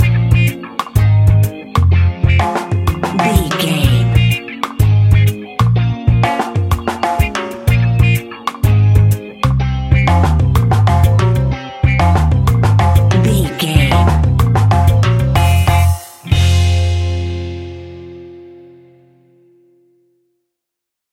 Aeolian/Minor
laid back
off beat
skank guitar
hammond organ
horns